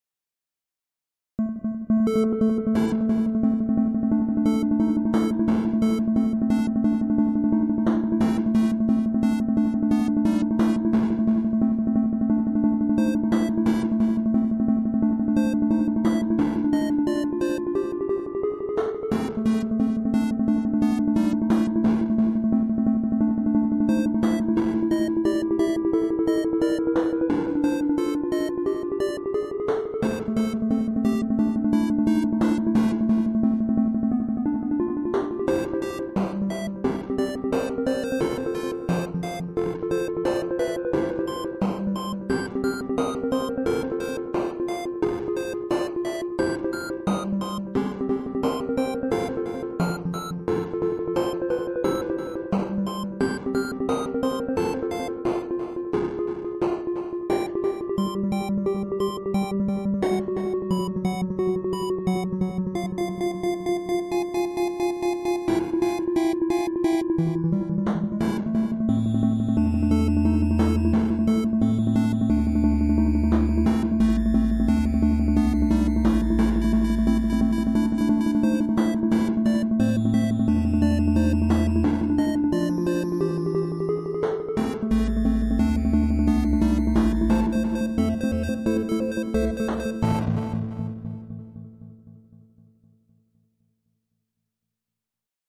I made my second song on the computer. I made it while thinking about an egg I used to know. The computer has a theramin on it. So I used that. It is not like a real theramin.